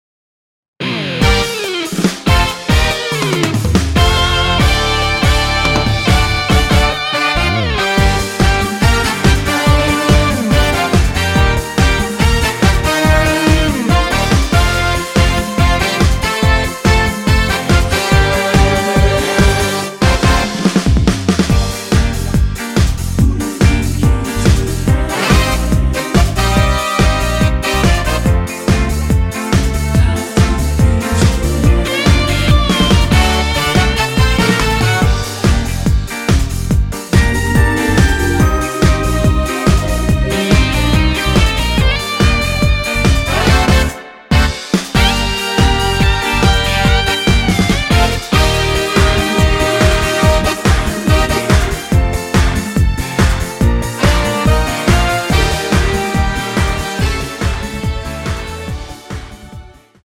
원키에서 (-2)내린 코러스 포하된 MR 입니다.(미리듣기 참조)
Gm
앞부분30초, 뒷부분30초씩 편집해서 올려 드리고 있습니다.